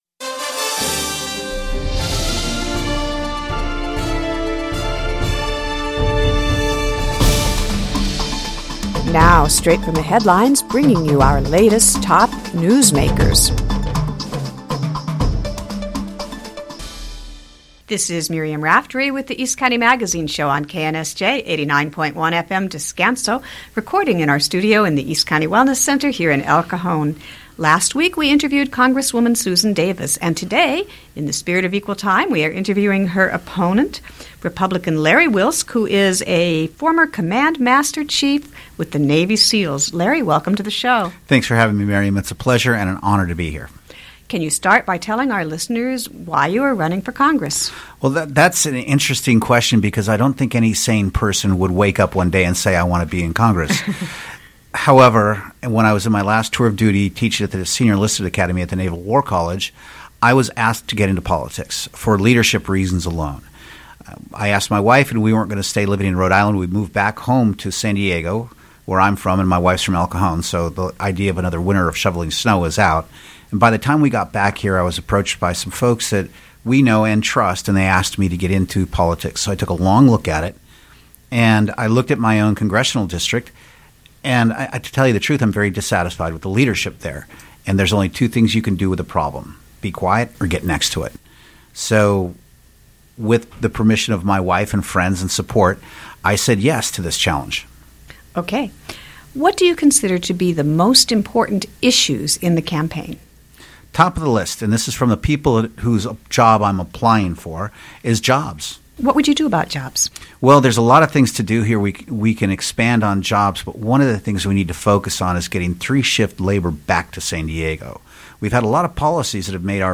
East County Magazine Live! Radio Show Listen to Podcasts Politics and local government Listen now!